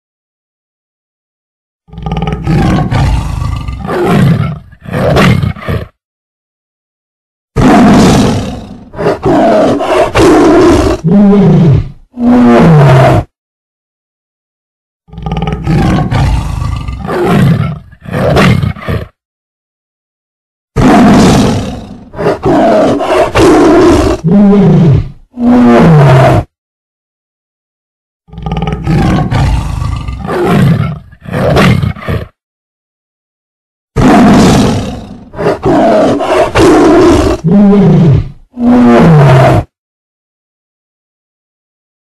دانلود آهنگ ببر 1 از افکت صوتی طبیعت و محیط
جلوه های صوتی
دانلود صدای ببر 1 از ساعد نیوز با لینک مستقیم و کیفیت بالا